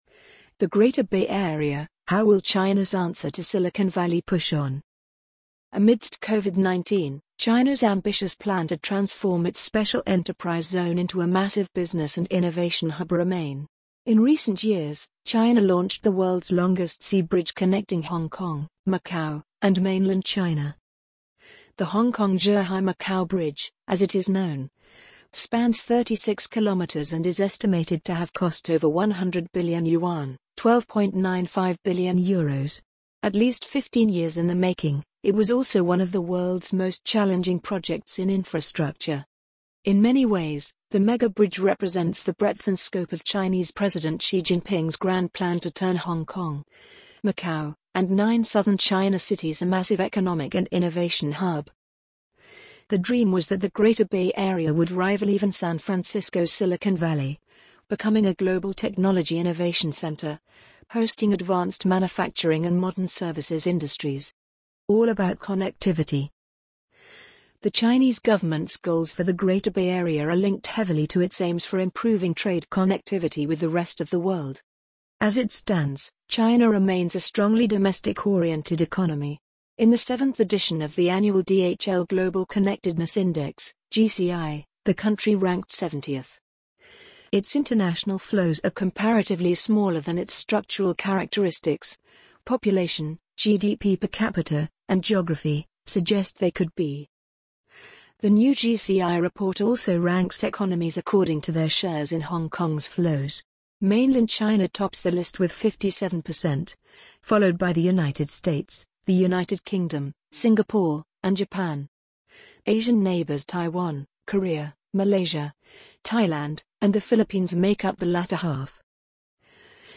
amazon_polly_10769.mp3